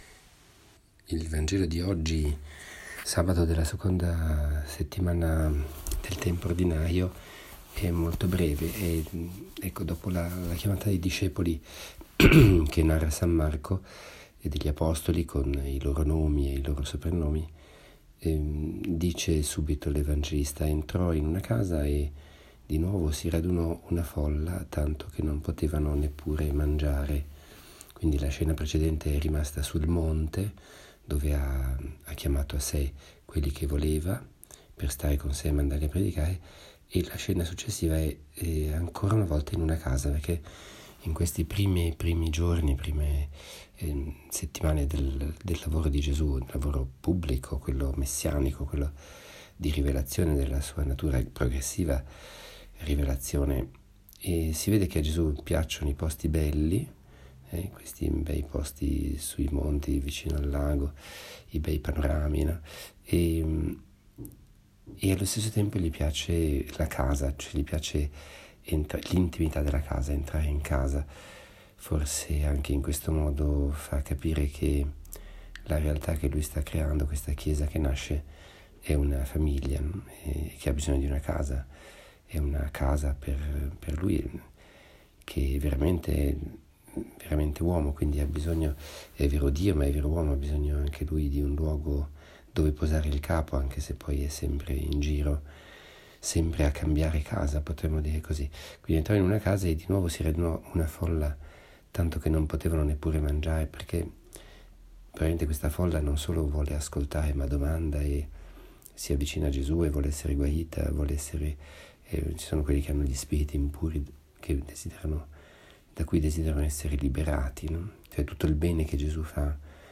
Commento al vangelo (Mc 3,20-21) del 20 gennaio 2018, sabato della II domenica del Tempo Ordinario.